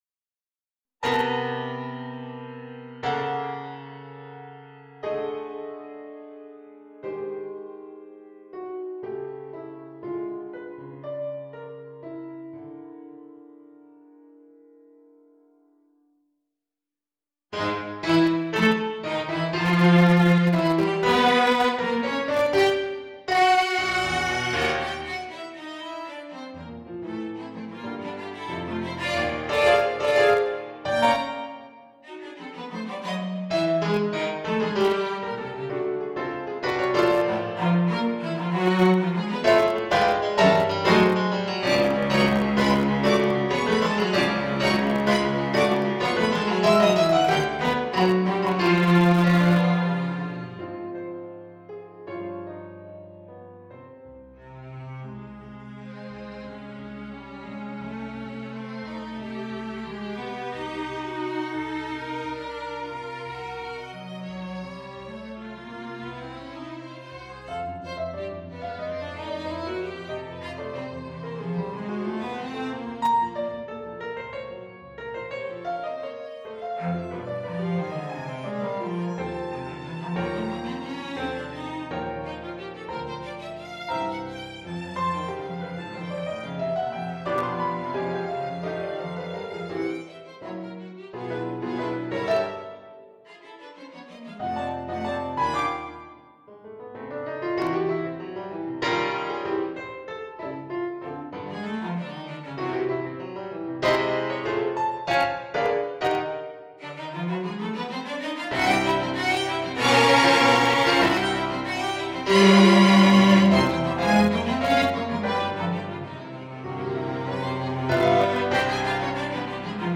Full Score Violin Part Cello Part Audio rendering
It is still concise, and perhaps even still excessively concise, but at least it can be followed. The work is described as being simply "in A", rather than A major or A minor, because much of the music combines major and minor, simultaneously or at least in close juxtaposition. The endings of the first and last movements present C (natural) and C# together.
TrioVnVcPfAOp18.mp3